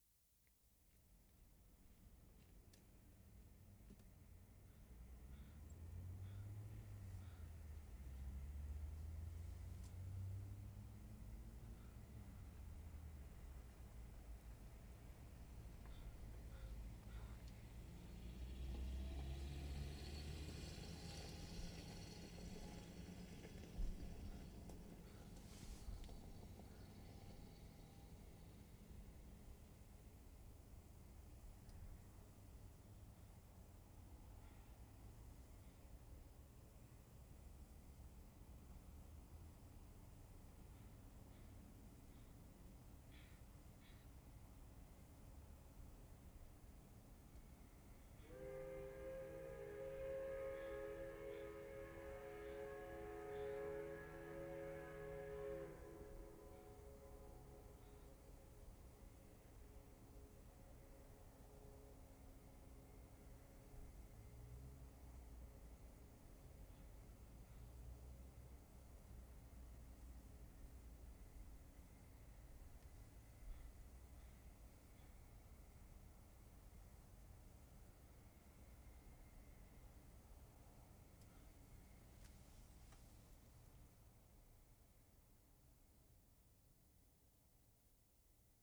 WORLD SOUNDSCAPE PROJECT TAPE LIBRARY
SHIFT WHISTLE, 4:30 p.m. 1'30"
This is an old steam whistle with three main notes in it.
*0'45" whistle toots.